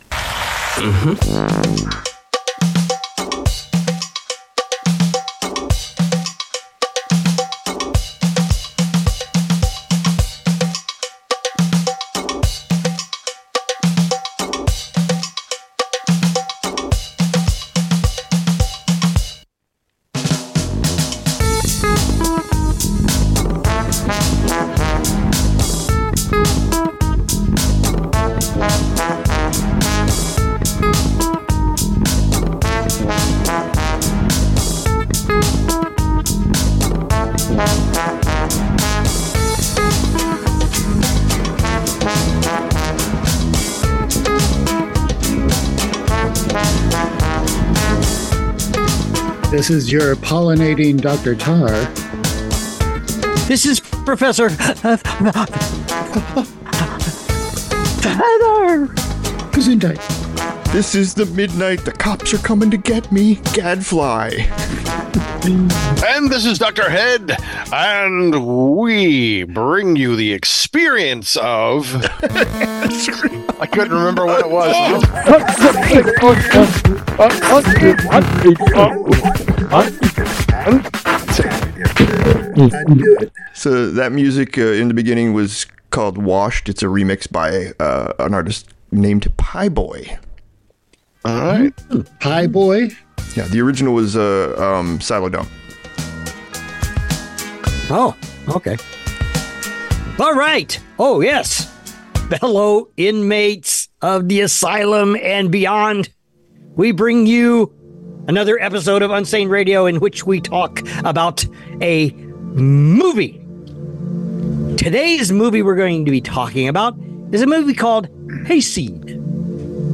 Your Hosts talk about the whodunit movie HAYSEED (2023), a comedy-drama set in a small town in Michigan.